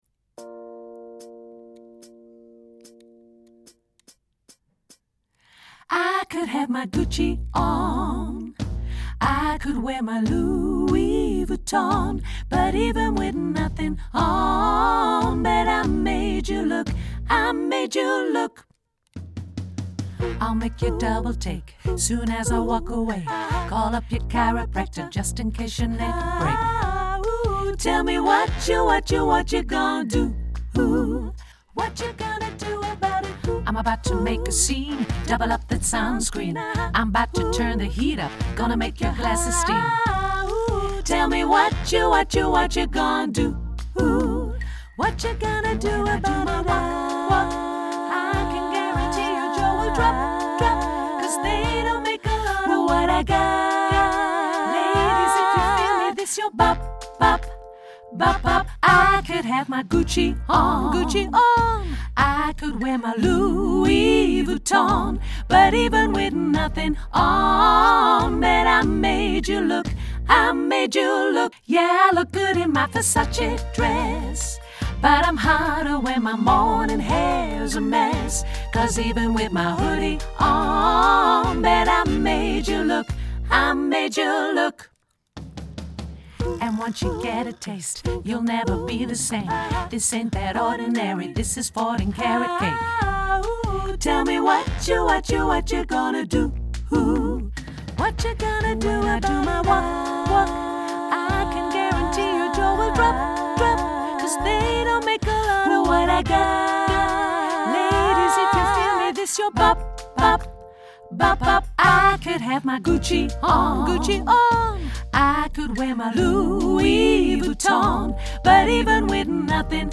tutti